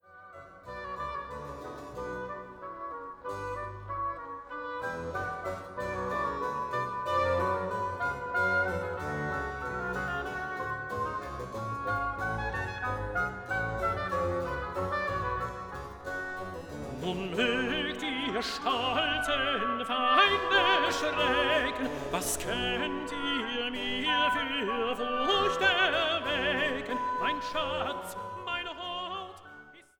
in Festgottesdiensten
in Mitschnitten der Uraufführungen
8. Rezitativ (T)